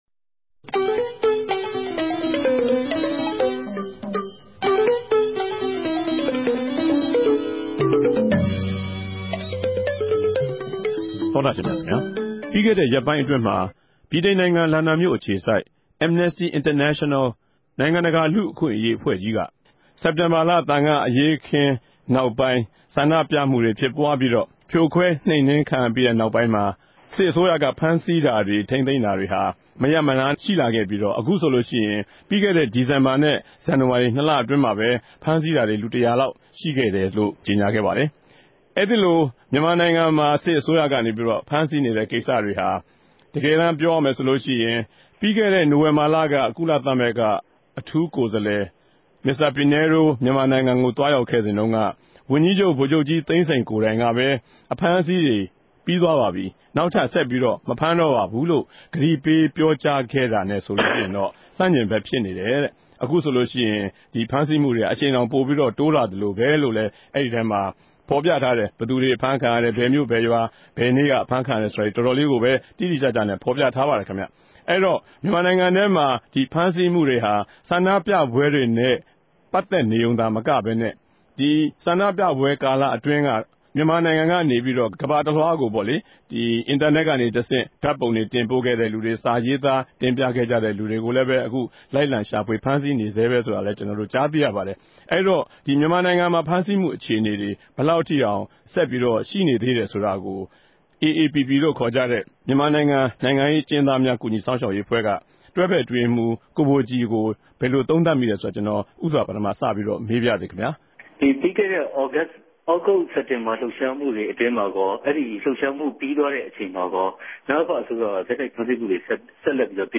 စကားဝိုင်း